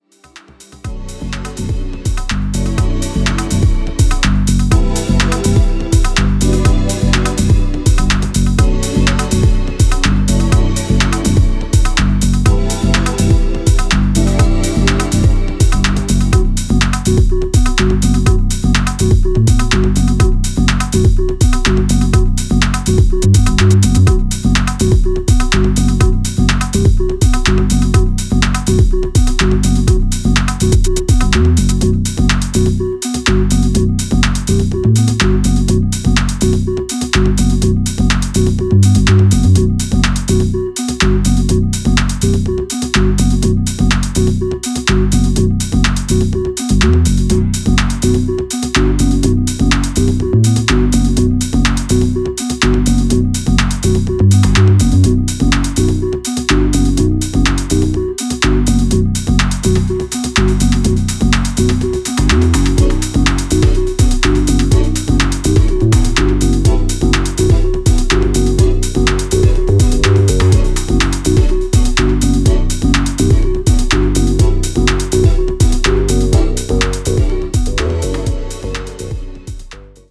Electro Electronix Techno Wave